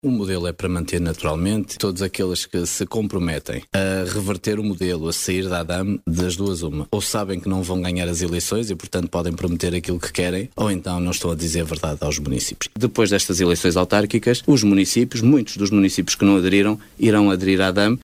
Debate Autárquicas 2021 – Rádio Caminha
De salientar que, nas autárquicas de 2021, aos microfones da Rádio Caminha, Miguel Alves afirmava, tanto em entrevista como em debate, que os municípios que não tinham aderido à AdAM iriam fazê-lo, mais cedo ou mais tarde, e os que “ameaçavam” sair estariam a mentir, por saberem que não iriam ganhar.